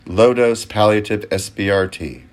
PLAY Crane 2
crane-2.mp3